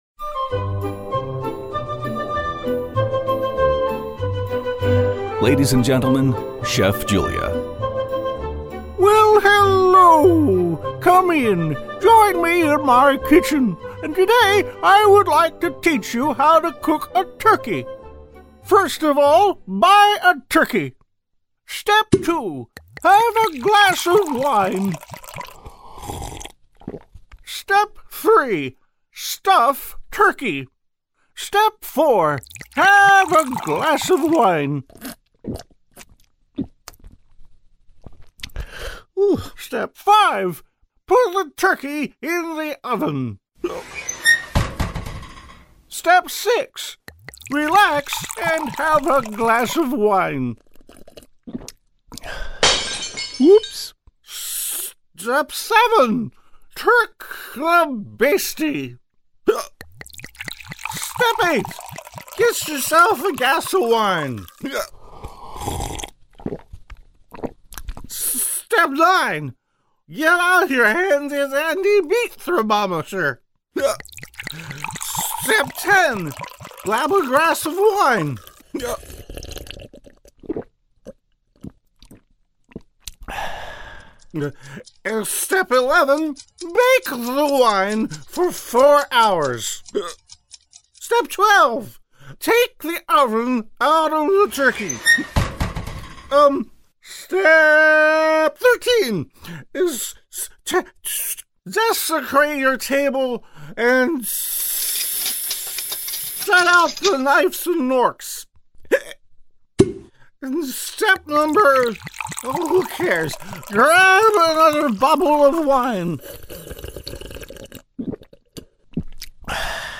My Thanksgiving gift to all my friends. A few laughs with "Chef Julia," a revival of an old radio bit I did years ago.